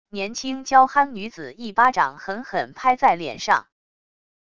年轻娇憨女子一巴掌狠狠拍在脸上wav音频